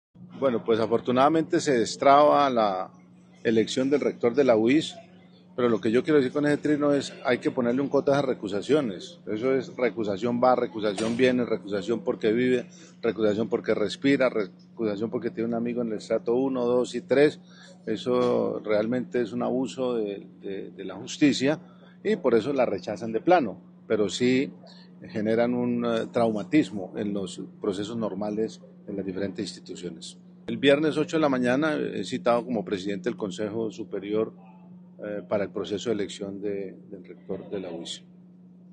Juvenal Díaz Mateus, Gobernador de Santander